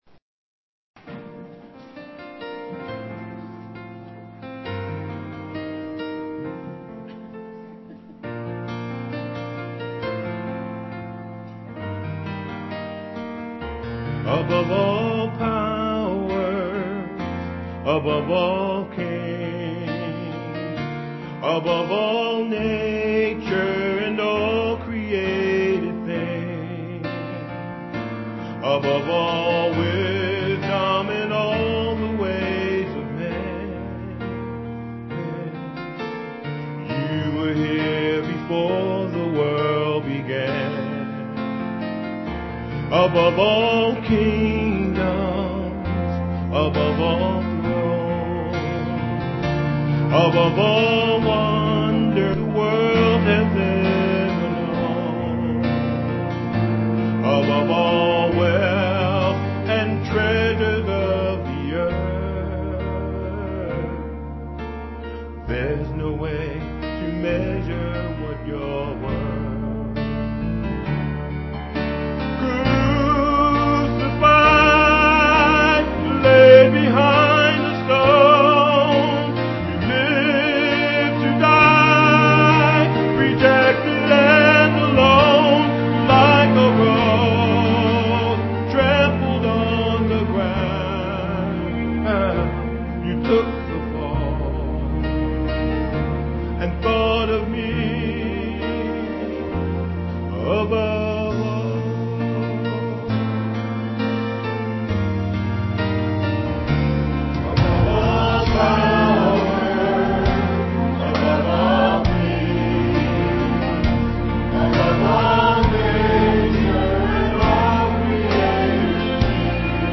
Piano offertory